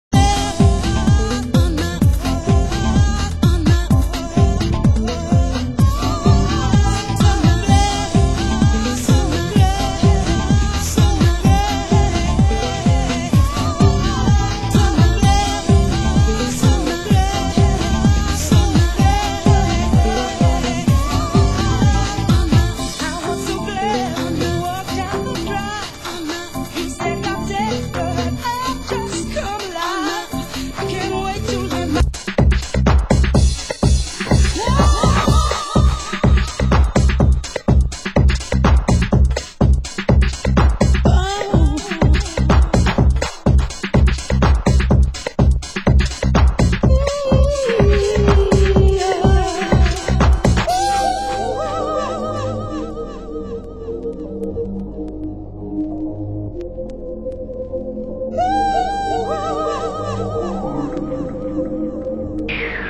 Genre: UK House
Genre: UK Garage